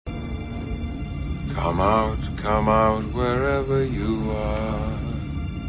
The Captain sings, short version